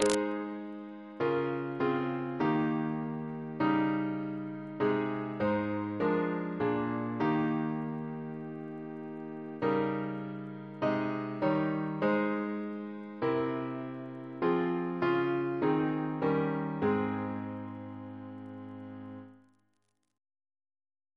Double chant in A♭ Composer: Edward John Hopkins (1818-1901), Organist of the Temple Church Reference psalters: ACB: 322; ACP: 190; OCB: 141; PP/SNCB: 206; RSCM: 70